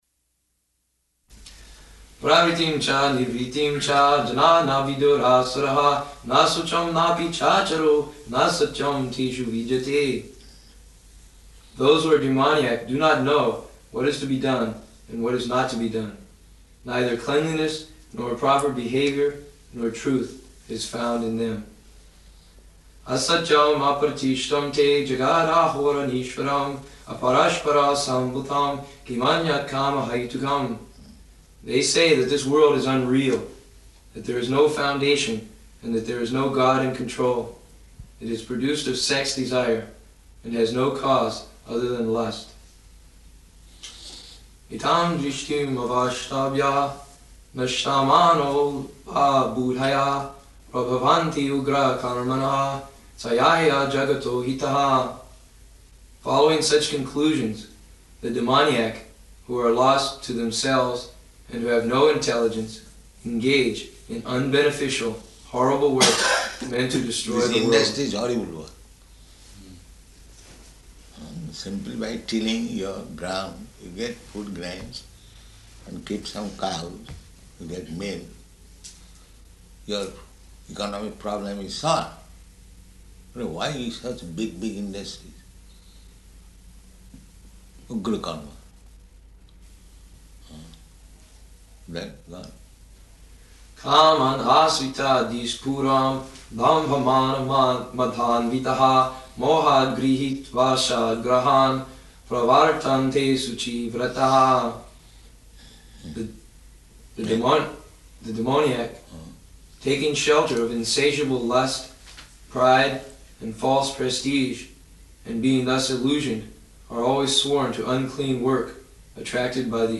Room Conversation, Bhagavad-gītā 16.7–23